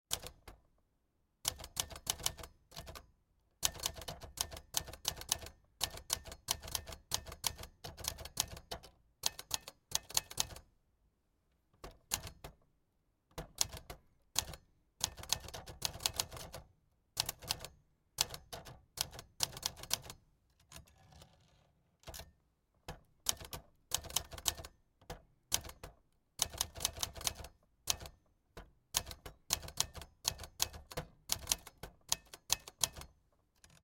Olivetti Dora typewriter